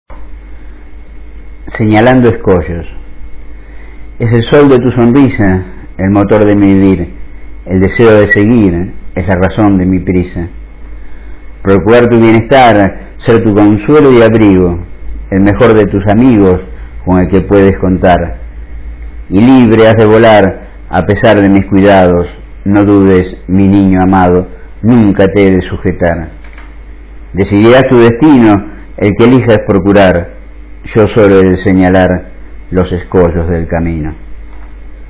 Recitado por el autor